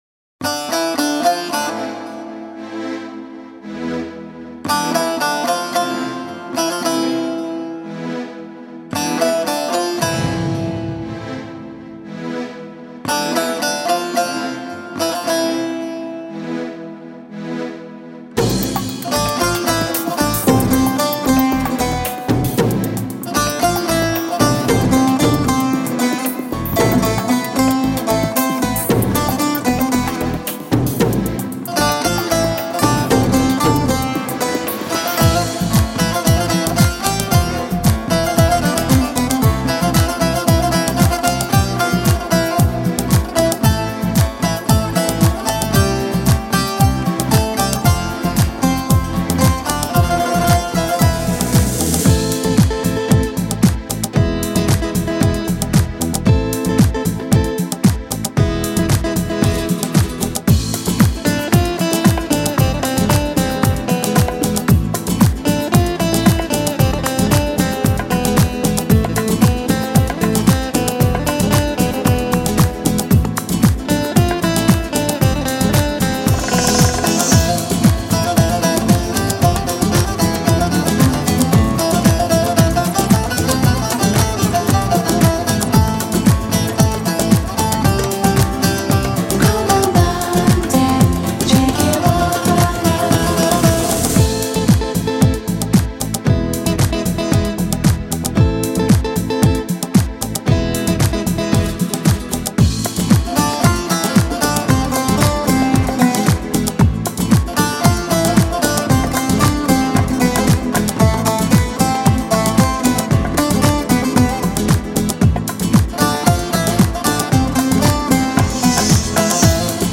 归属为鲁特族（Lute Family）乐器的巴拉玛（Baglama），
Baglama的音色是纤细甚至孱弱的，
听上去象俄罗斯的三角琴。
声音不高亢而清脆有力，
节拍虽繁复密集．
但听来非常流畅、并不拖泥带水。